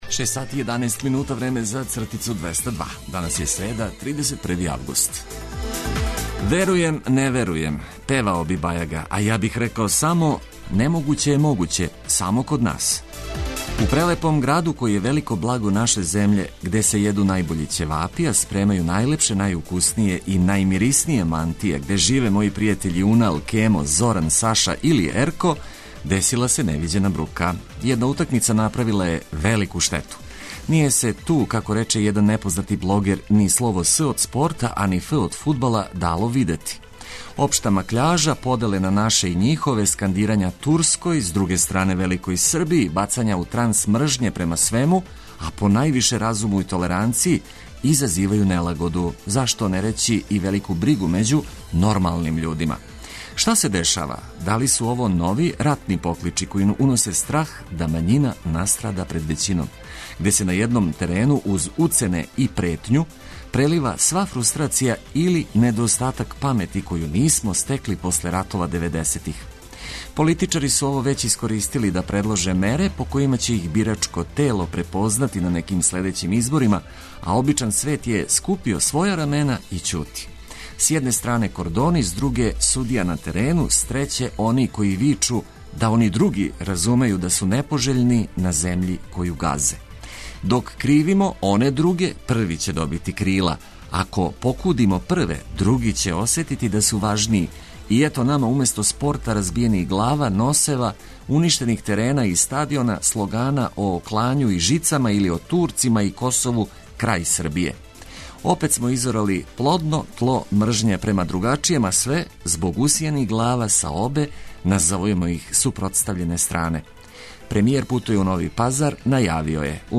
Ведро буђење уз приче од користи и весела музика за разбуђивање.